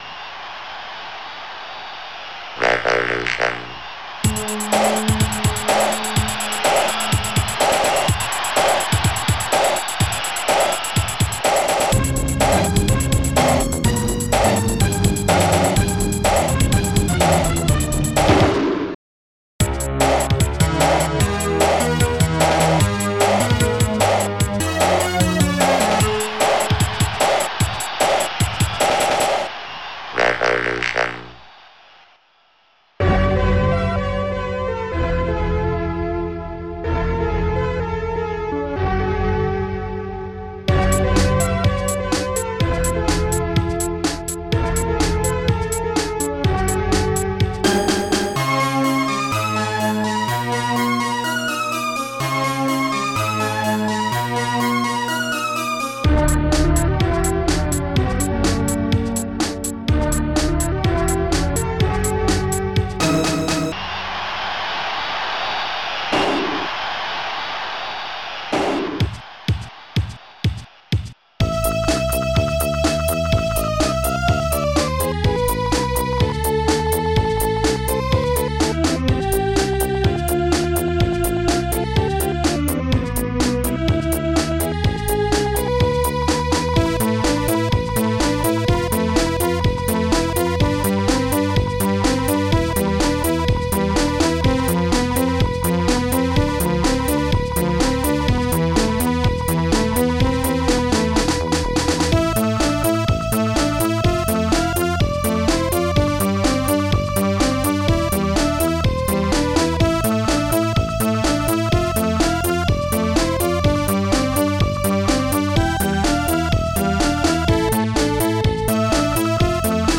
st-01:hihat1
st-01:strings6
st-02:snare9
st-13:d-50harpsi